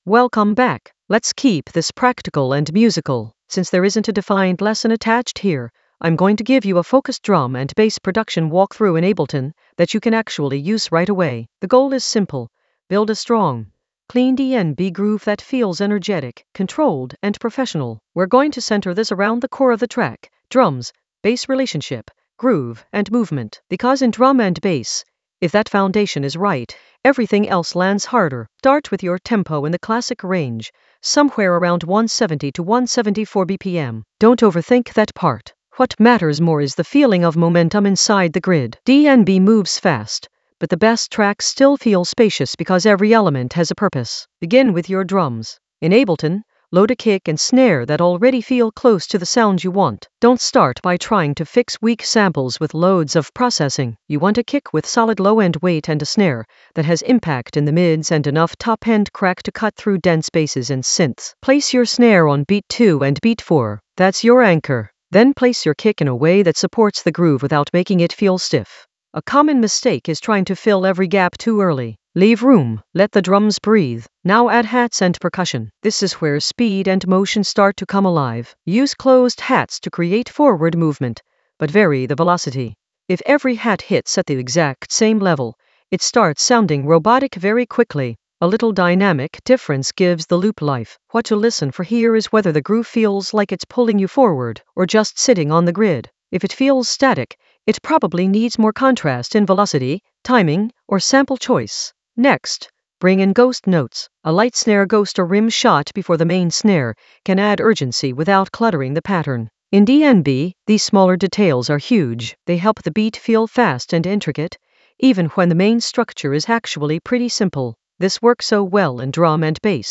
An AI-generated intermediate Ableton lesson focused on Bass Generator blueprint: build a rave-stab intro in Ableton Live 12 for drum and bass pressure in the Arrangement area of drum and bass production.
Narrated lesson audio
The voice track includes the tutorial plus extra teacher commentary.